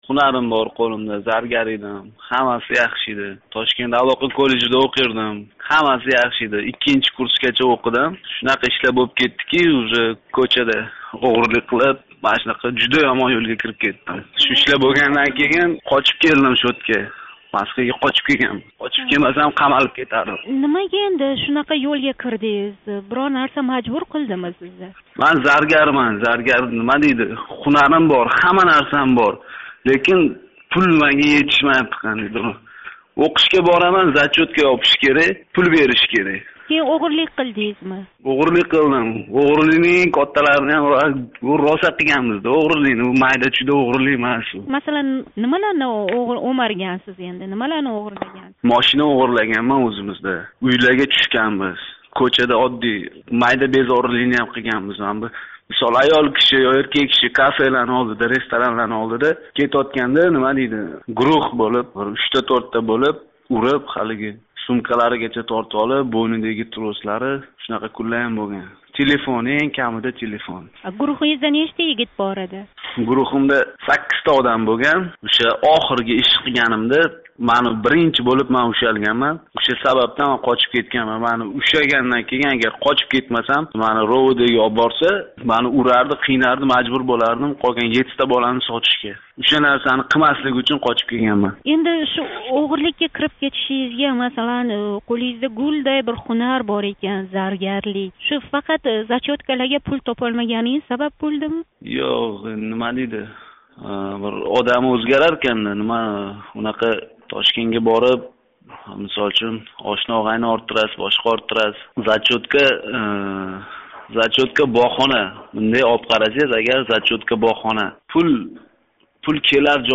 Собиқ ўғри билан суҳбат